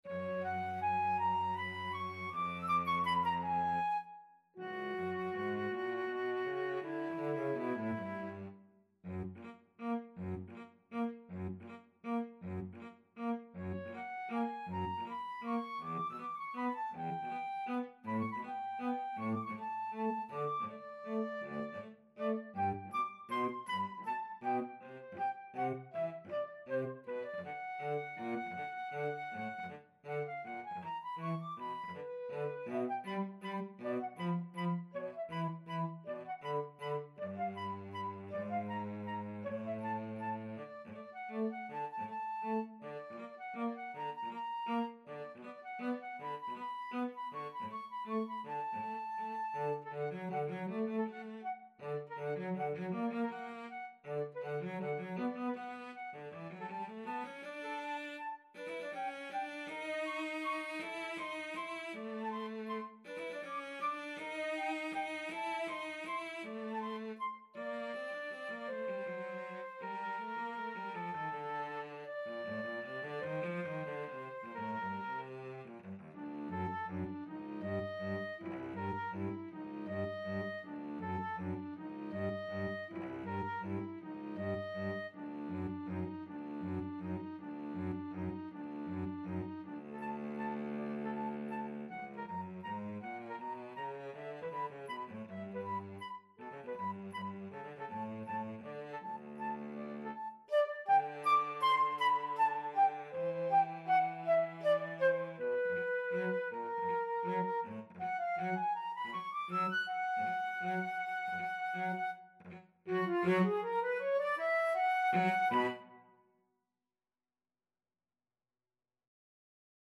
Free Sheet music for Flute-Cello Duet
FluteCello
3/4 (View more 3/4 Music)
D major (Sounding Pitch) (View more D major Music for Flute-Cello Duet )
Allegretto = 160
Classical (View more Classical Flute-Cello Duet Music)